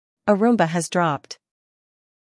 Roomba Speech Botão de Som